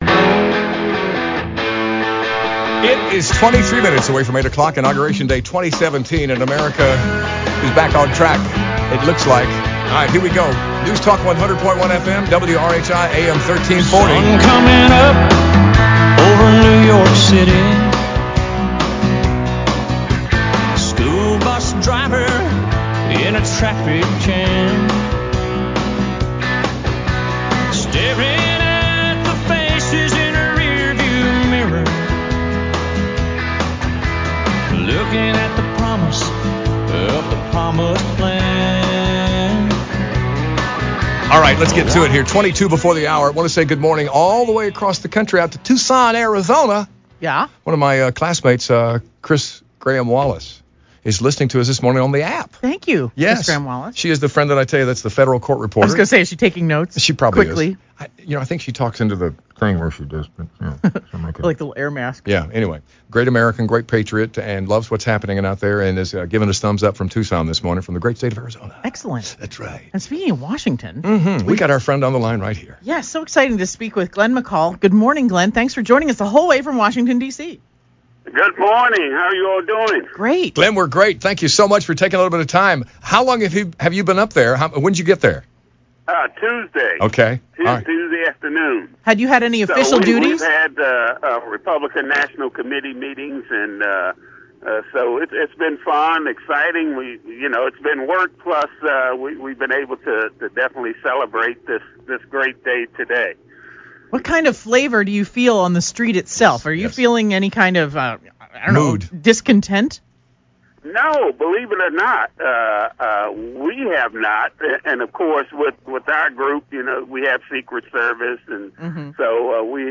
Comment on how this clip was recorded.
called Palmetto Mornings to give his perspective on the mood in our nation’s capitol.